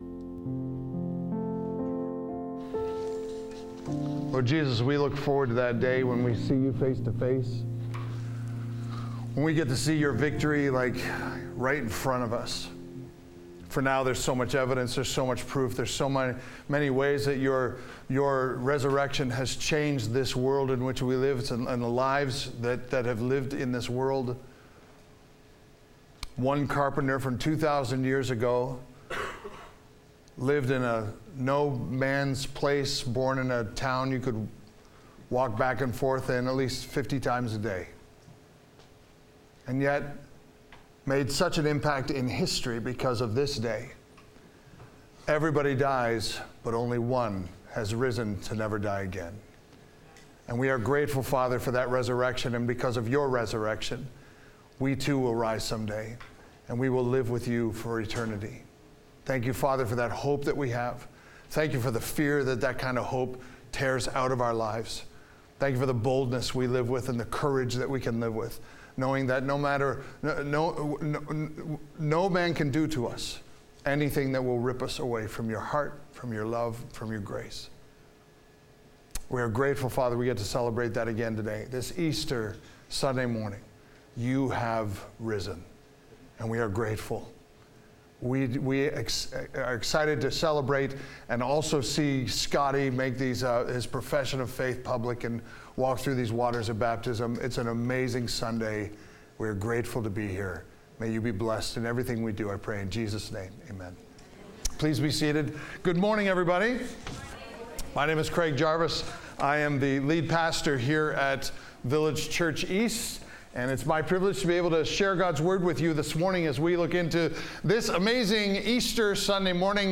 This Easter Sunday we discover why the fact that Jesus has risen is important. Jesus raised from the dead so that we could see with understanding how our lives changed forever ever since that day on.